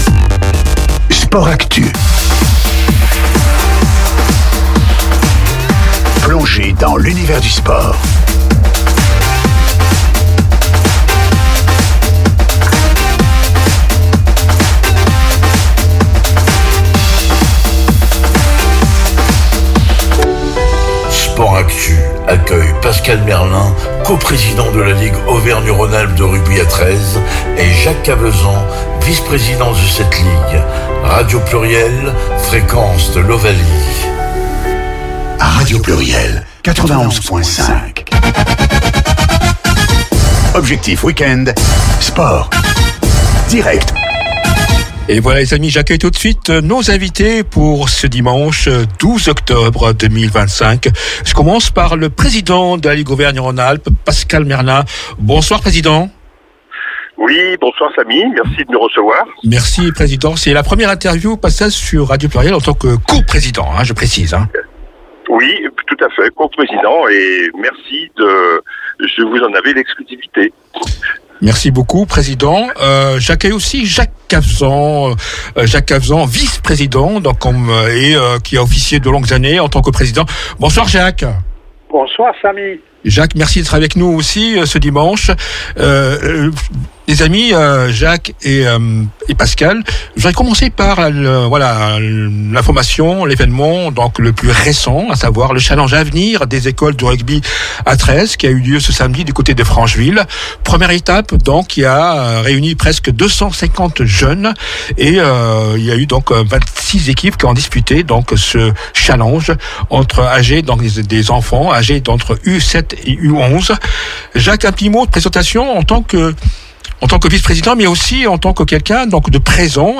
L’interview du président mois de AVRIL 2026